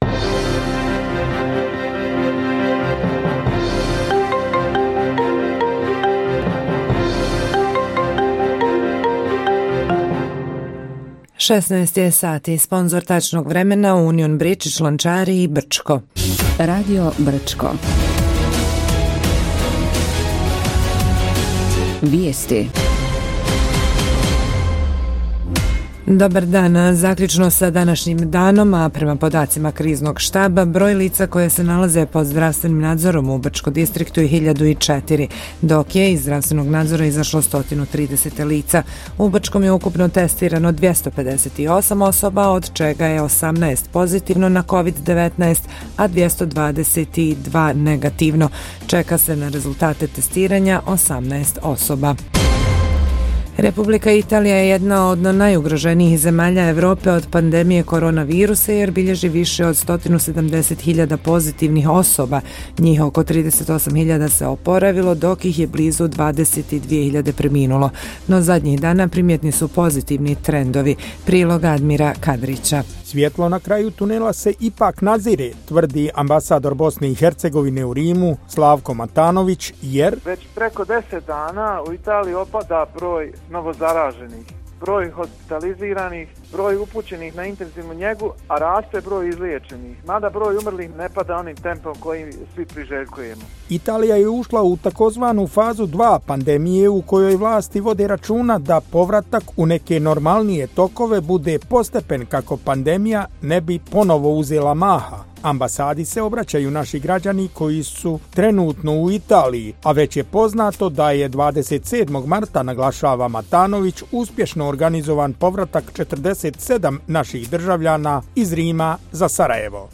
Радио Брчко: Вијести за четвртак 16.04.2020. године